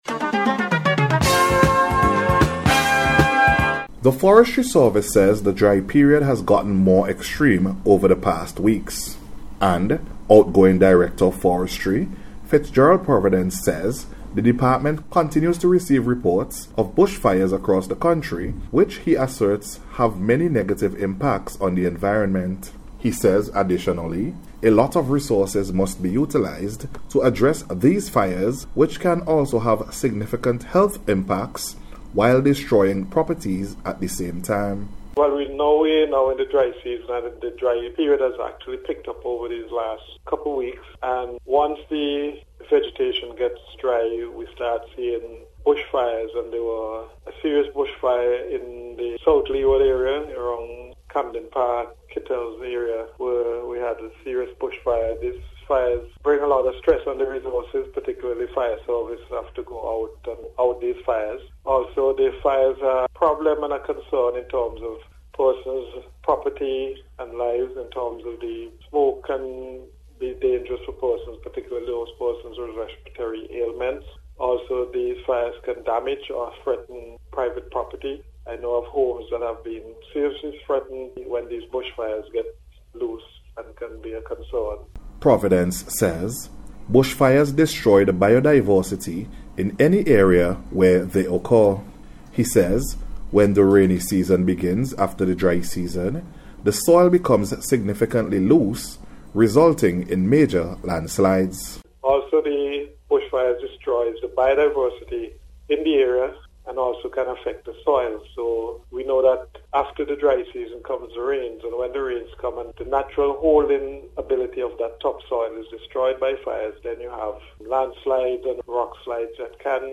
DRY-SEASON-ENVIRONMENTAL-PROTECTION-REPORT.mp3